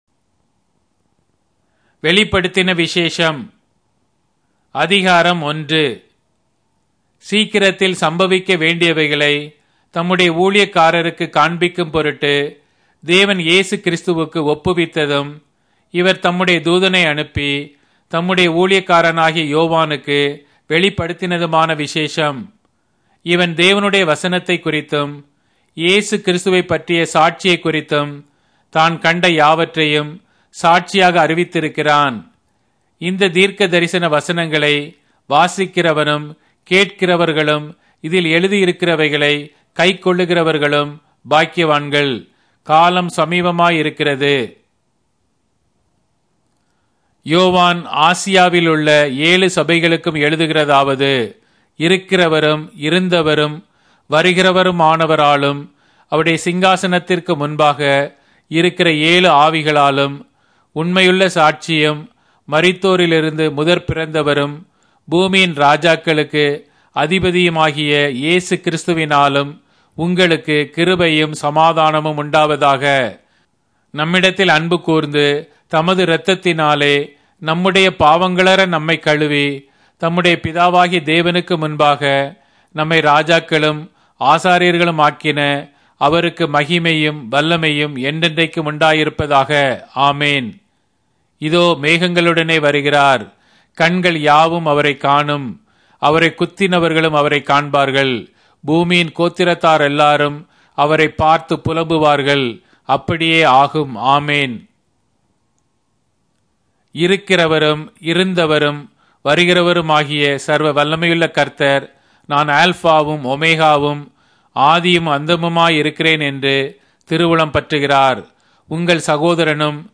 Tamil Audio Bible - Revelation 3 in Mov bible version